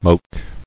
[moke]